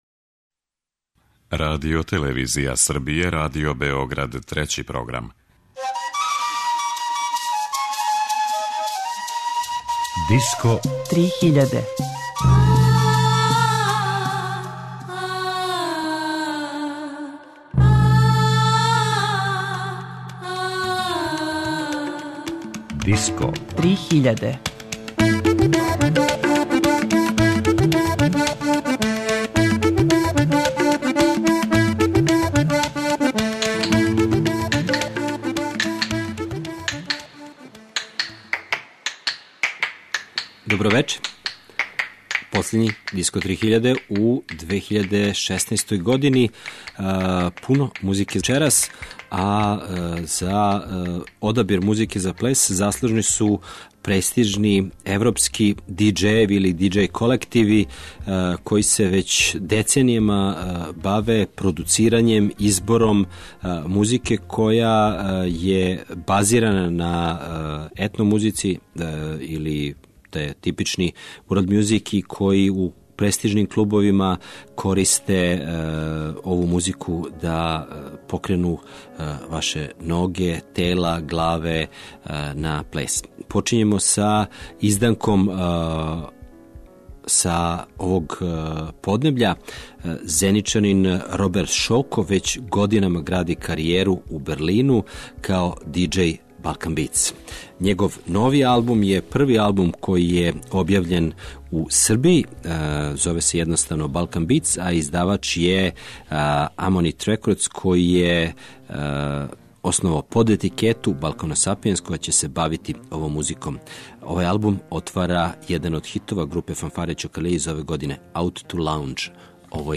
Већ годинама европски диск-џокеји, инспирисани светском музиком креирају нови музички свет, погодан за клубове у којима публика долази да плеше.
Disco 3000 је емисија посвећена world music сцени, новитетима, трендовима, фестивалима и новим албумима.